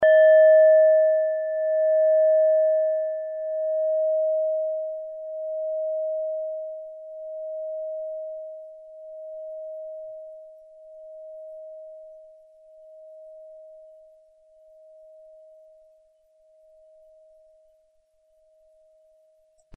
Nepal Klangschale Nr.20, Planetentonschale: Wasserstoffgamma
(Ermittelt mit dem Filzklöppel)
Die Klangschale hat bei 629.17 Hz einen Teilton mit einer
Die Klangschale hat bei 1757.64 Hz einen Teilton mit einer
klangschale-nepal-20.mp3